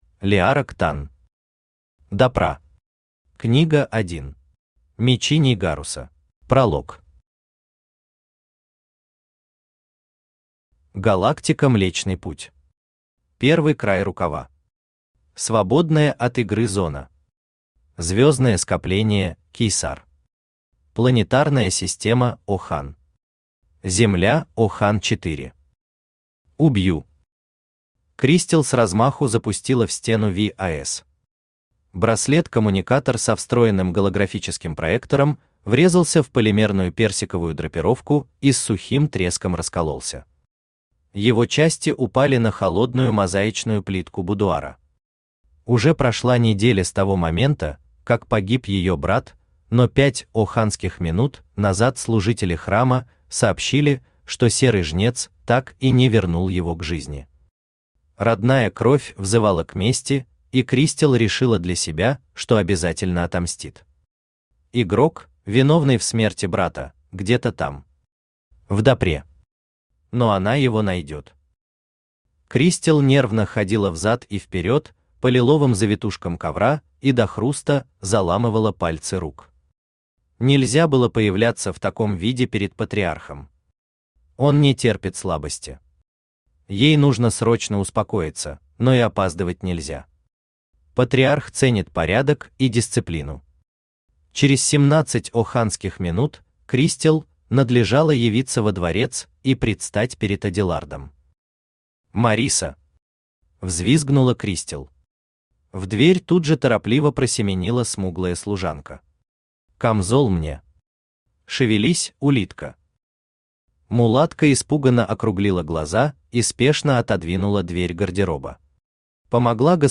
Аудиокнига Допра. Книга 1. Мечи Нейгаруса | Библиотека аудиокниг
Мечи Нейгаруса Автор Леарок Танн Читает аудиокнигу Авточтец ЛитРес.